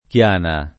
kL#na] top. f. (Tosc.) — con c- minusc. se inteso come nome comune («luogo paludoso», qual era la val di Chiana dal Medioevo fin quasi all’800): Per boschi, per burron, per mille chiane [per b0Ski, per burr1n, per m&lle kL#ne] (Luigi Pulci) — anche Chiane sing. come forma pop. d’uso locale, del resto originaria, per il top. f. — cfr. Chiani